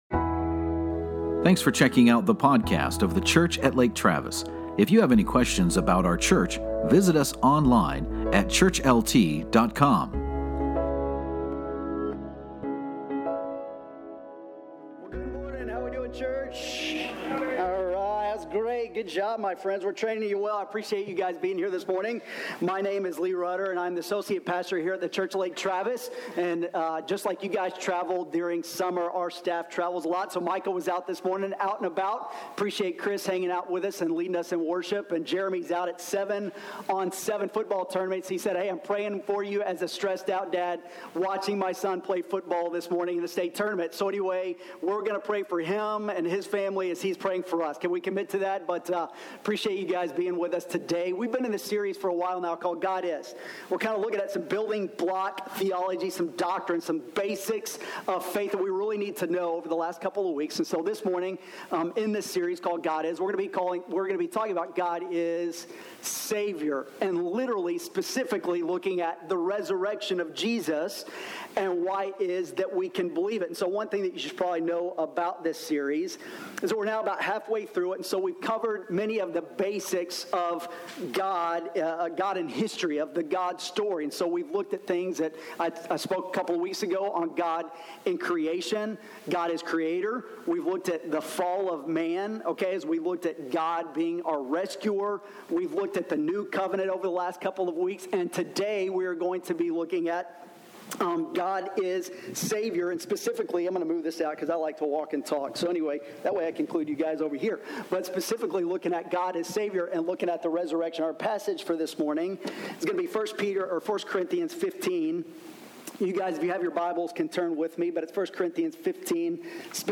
In this sermon, we explore the Biblical and circumstantial evidence of Jesus’ bodily resurrection, then deal with common objections to this doctrine.